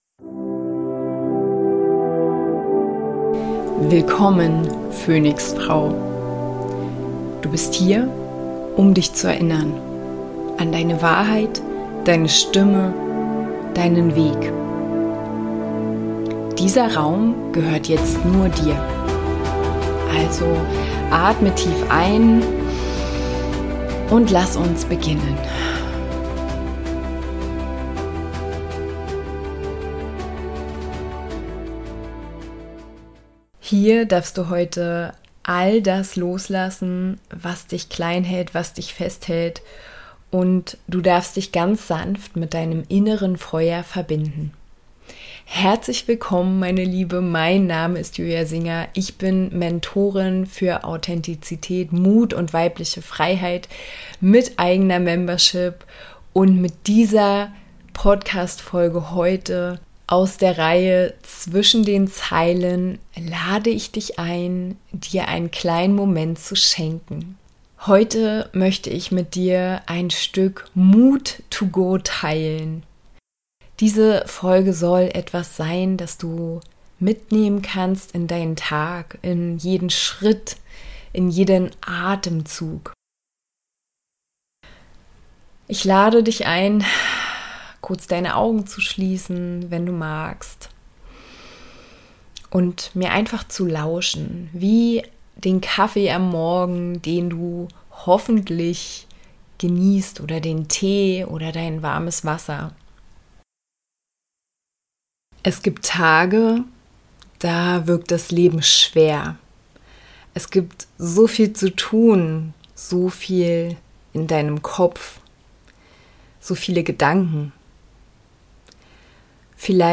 In dieser Folge aus der Reihe 'Zwischen den Zeilen' schenke ich dir eine kurze, meditative Reise, die Dir innere Stärke, Gelassenheit und Zuversicht wecken darf.